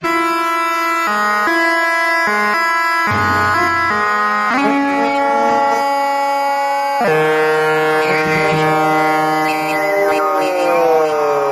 Tags: Science/Nature Sounds Recorded in Space Sci-Fi Skylab Sputnik Program